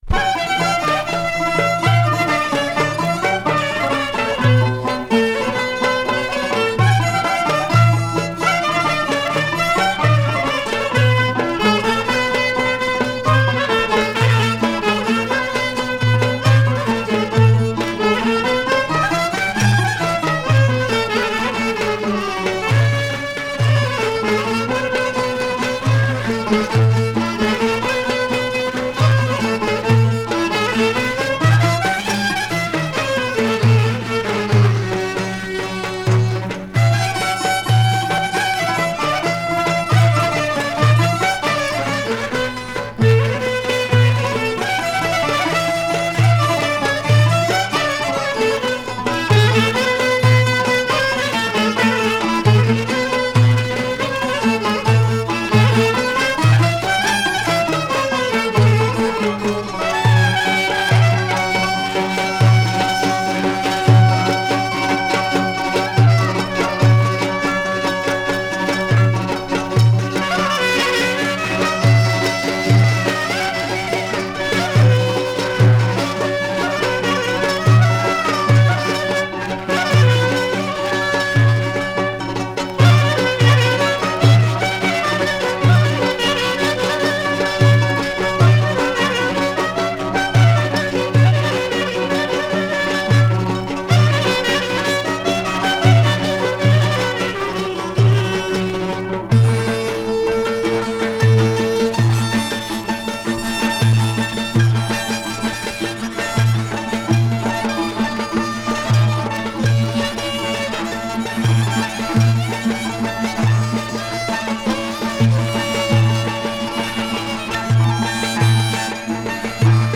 Genre: National Folk
Comment: (Distorted, dirt on stylus) (Incomplete)